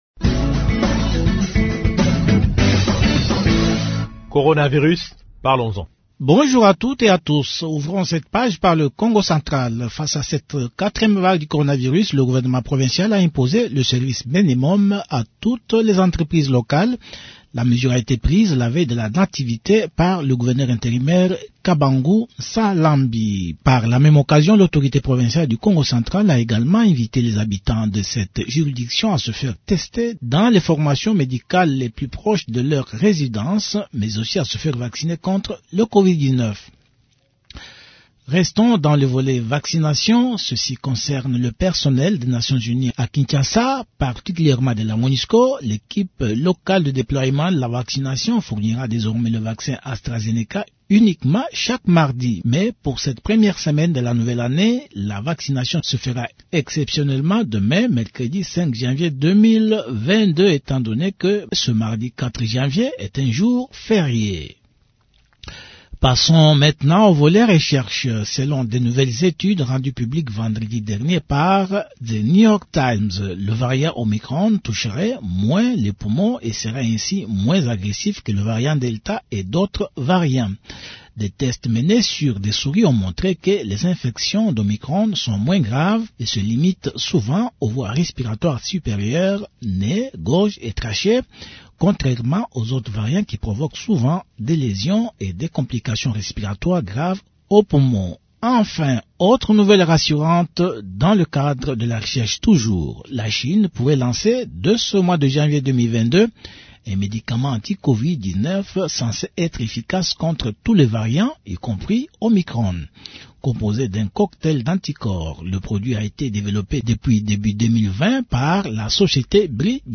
Réécoutons encore le gouverneur de province intérimaire de la Tshopo qui a pris une série de mesures sanitaires pour stopper la propagation du coronavirus dans sa juridiction.